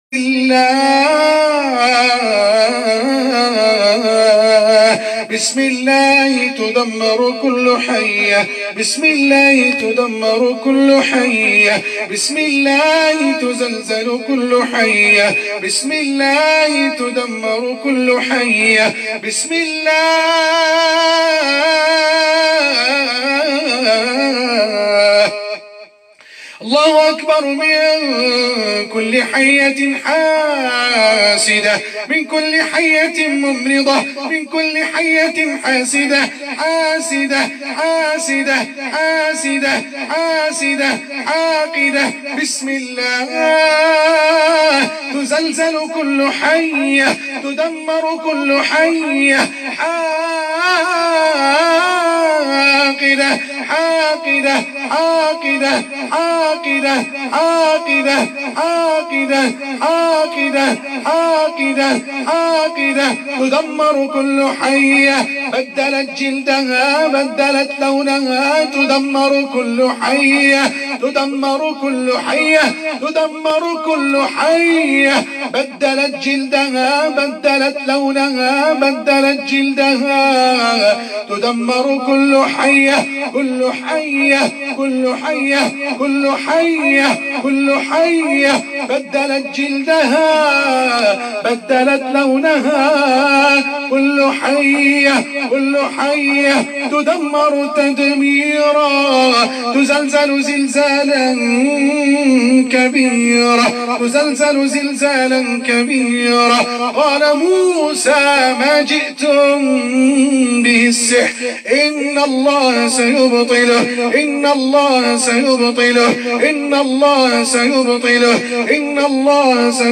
সাপ জ্বীনের রুকইয়াহ — Ruqyah for snake jinn